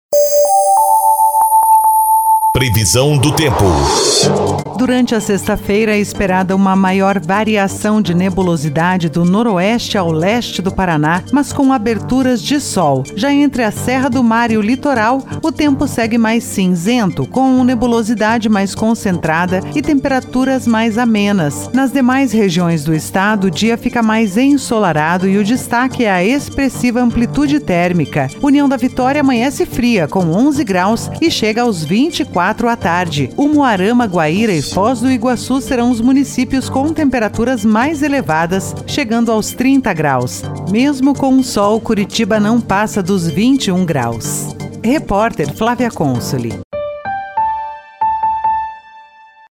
Previsão do Tempo 06/01/2023